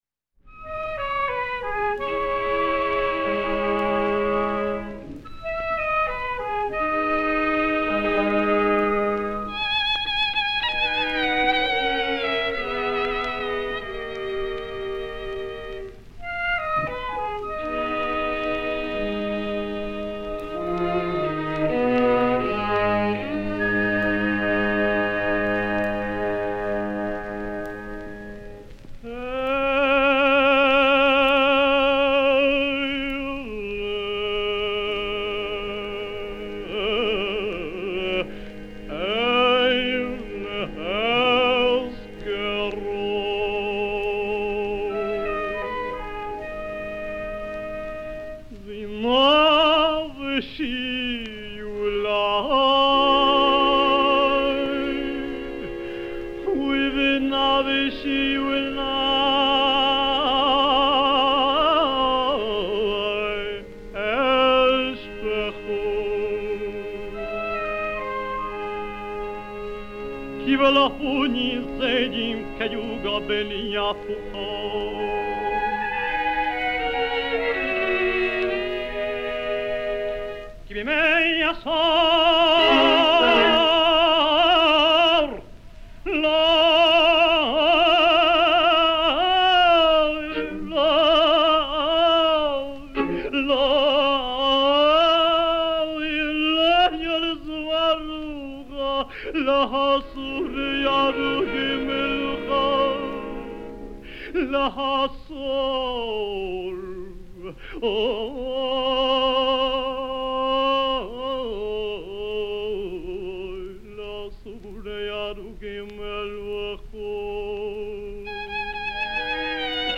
Pierre Pinchik sings
It didn't quite meet Pinchik's musical background, though: the congregation of that synagogue was accustomed to music in the austere German-Jewish taste, pretty different from Pinchik's Sephardic style rich of ornaments and emotional outbursts.